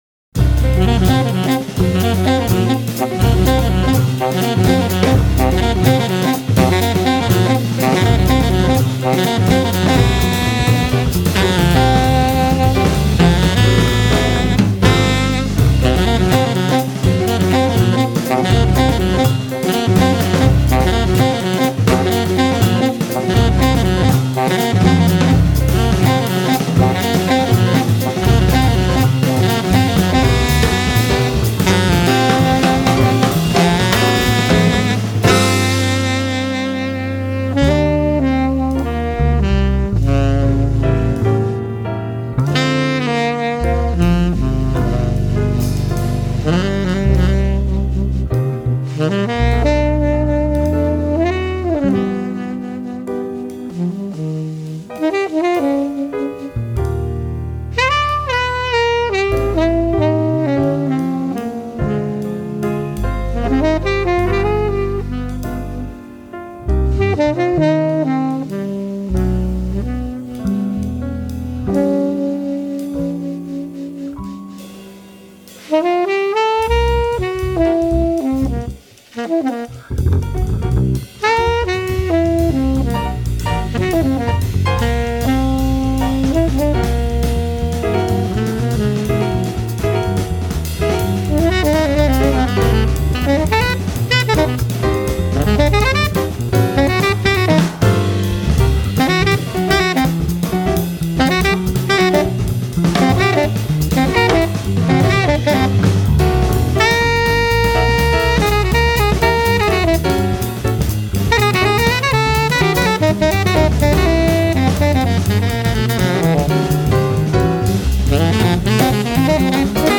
tenor sax
acoustic bass
drums